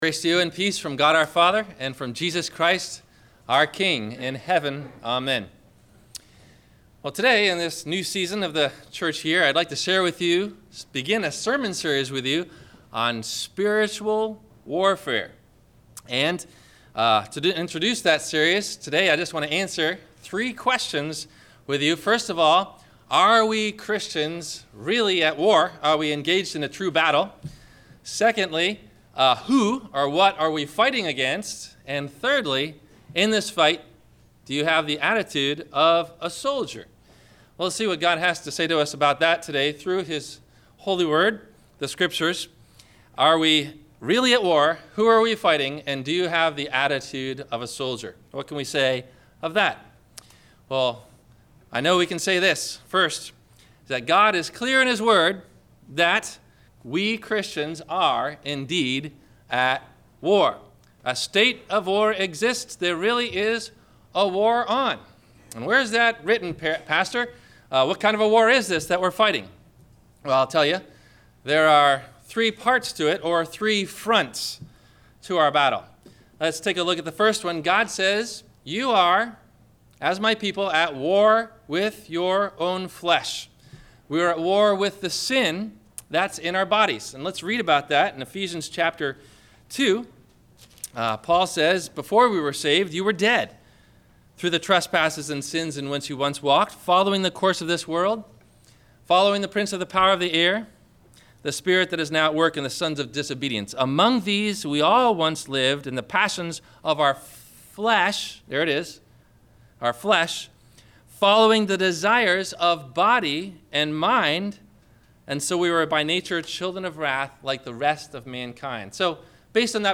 (Christian) - Sermon - February 22 2015 - Christ Lutheran Cape Canaveral
Do You Have the Attitude of A Soldier? (Christian) – Sermon – February 22 2015